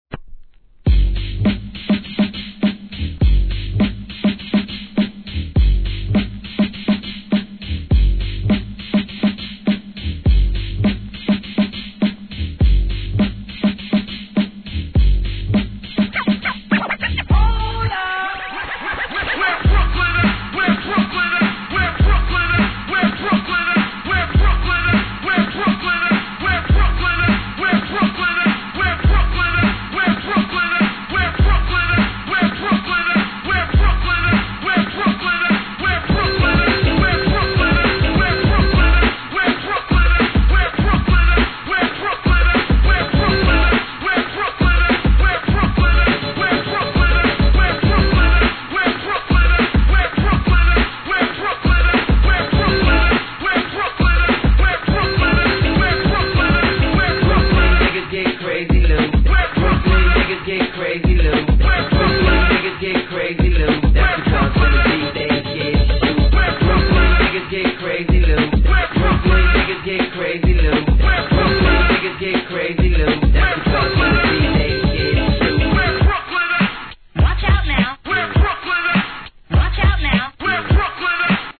HIP HOP/R&B
往年のヒット曲をミックスしたパーティー仕様!!